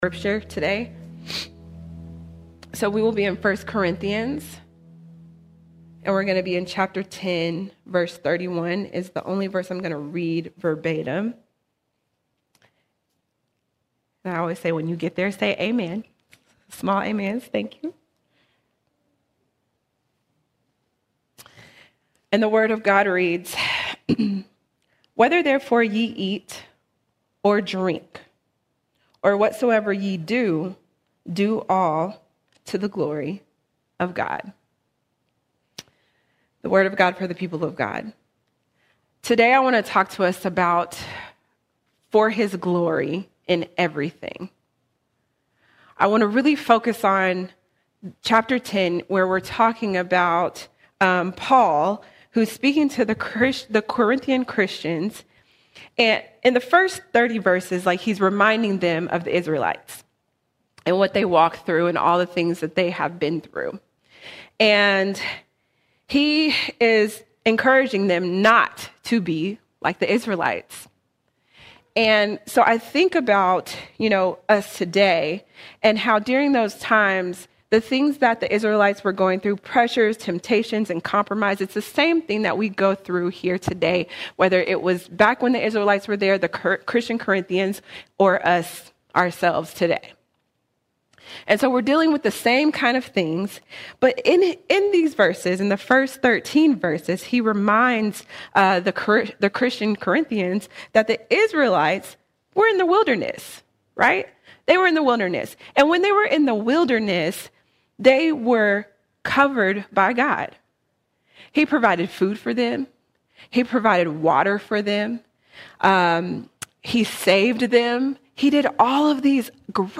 27 January 2026 Series: Sunday Sermons All Sermons For His Glory: In Everything For His Glory: In Everything We’ve been called to live God-centered lives.